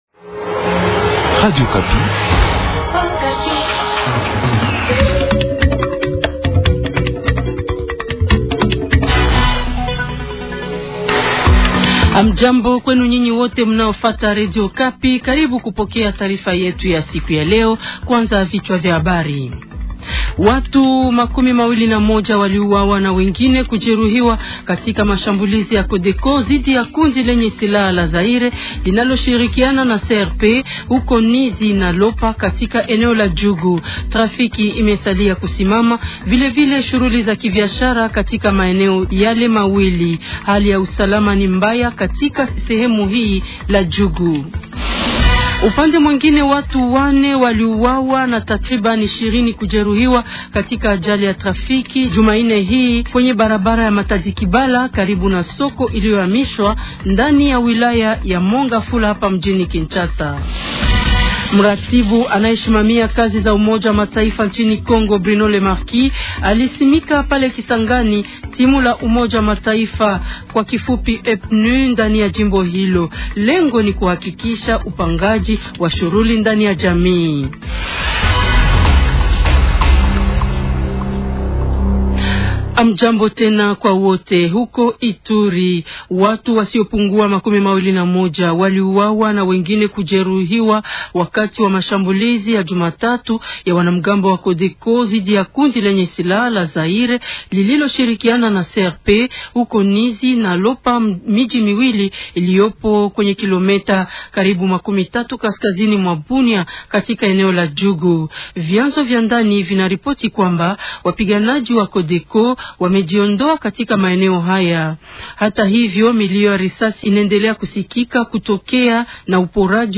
journal swahili matin